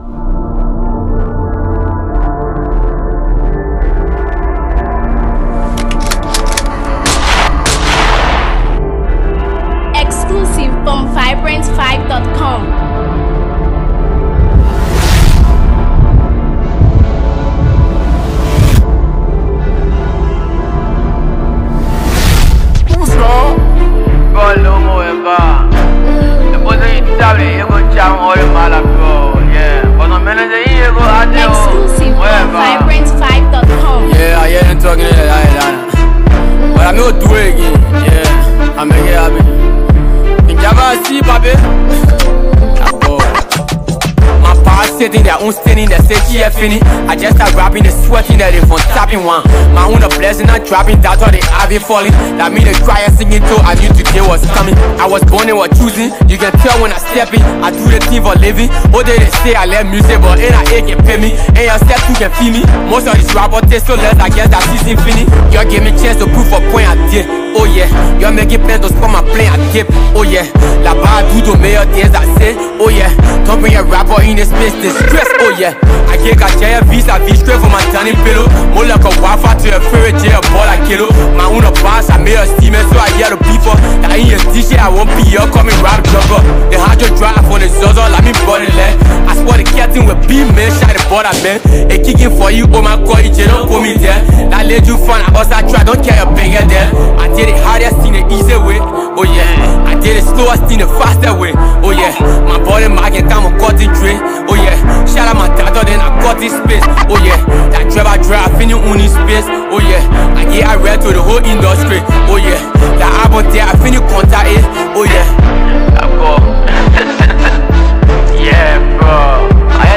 powerful and uplifting track